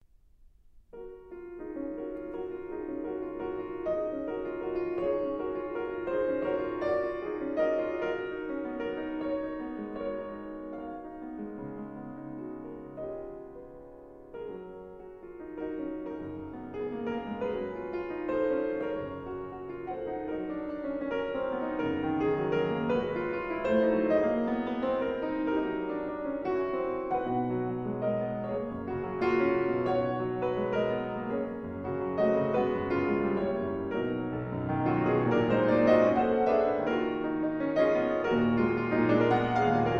in F minor: Allegro con fuoco